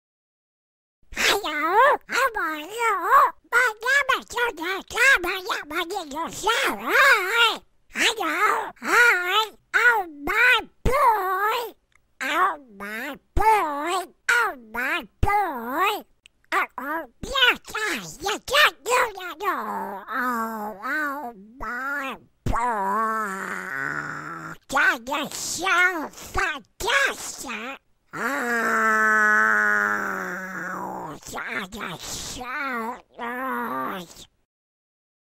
Голос утки Дональда Дака персонаж из мультфильма говорит что-то